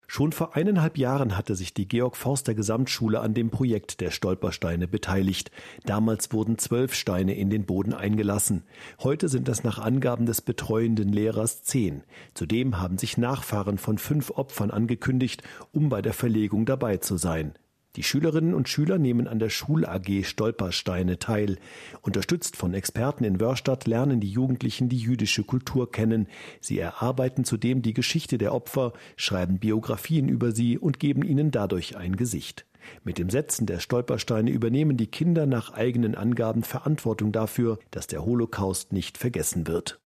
Auch die Allgemeine Zeitung Alzey-Worms [Externer Inhalt], sowie in den SWR 4 Nachrichten berichteten über unser Projekt.
SWR-4-Schueler-verlegen-10-Stolpersteine-in-Woerrstadt.mp3